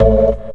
54_04_organ-A.wav